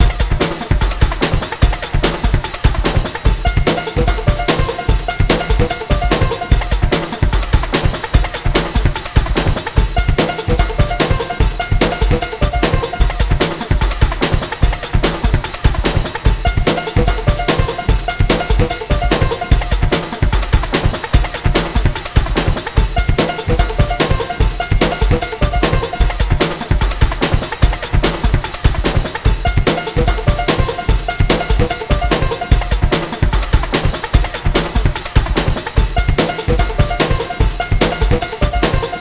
スピード感ある乗りの良いループサウンド。